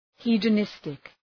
Προφορά
{,hi:də’nıstık}